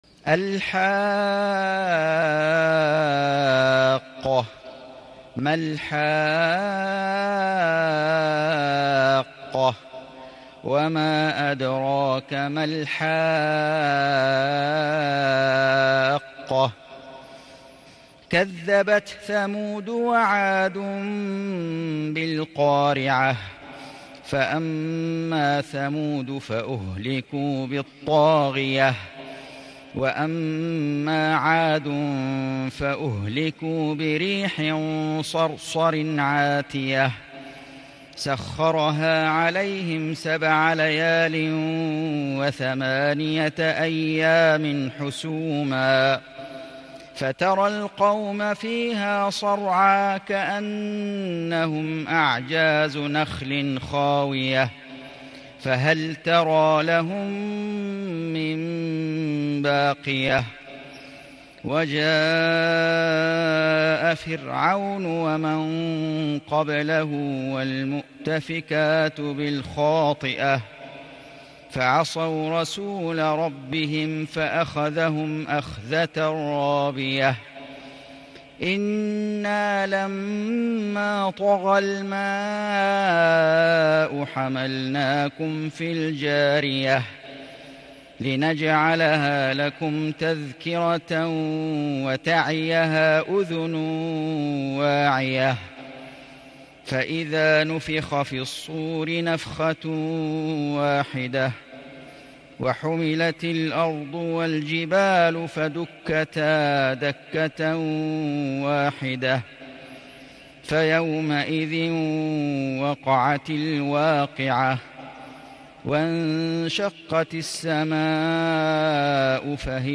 سورة الحاقة > السور المكتملة للشيخ فيصل غزاوي من الحرم المكي 🕋 > السور المكتملة 🕋 > المزيد - تلاوات الحرمين